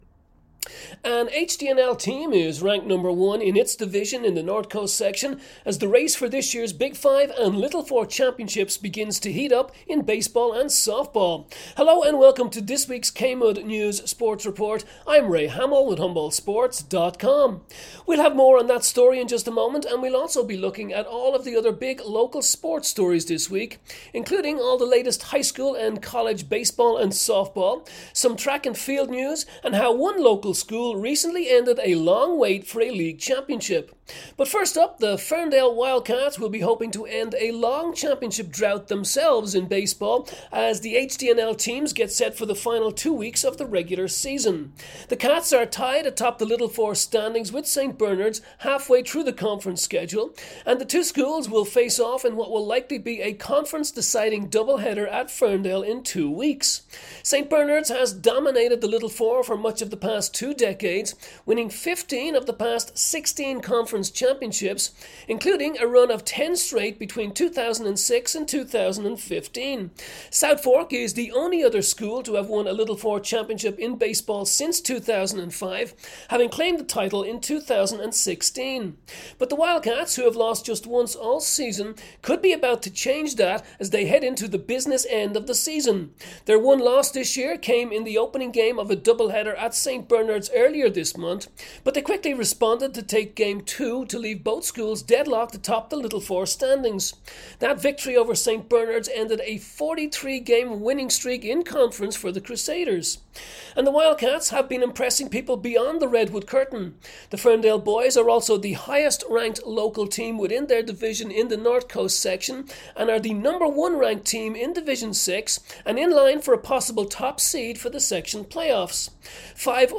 APRIL 18 KMUD Sports Report